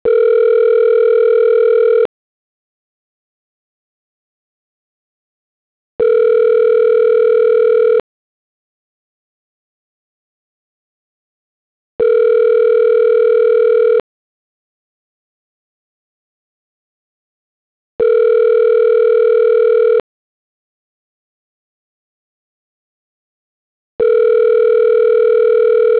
ringback.wav